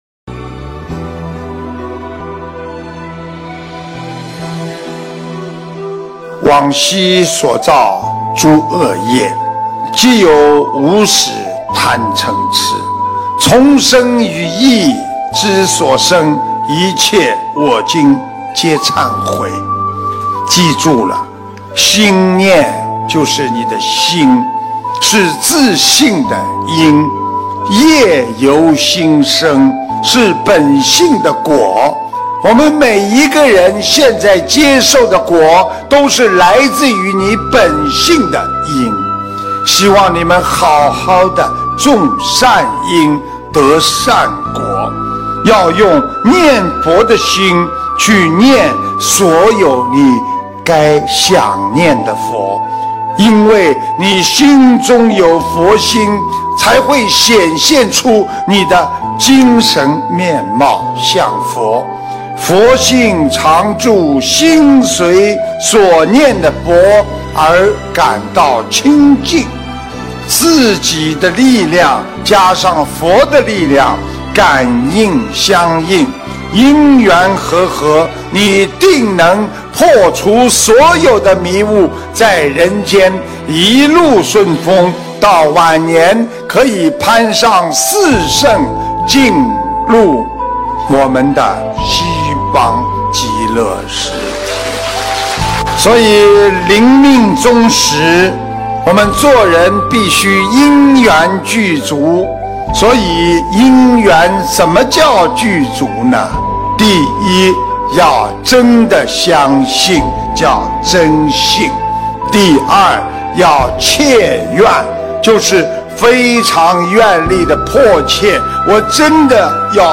视频：110.心中常存慈悲心！心明见性菩提境！法国世界佛友见面会！2019年9月6日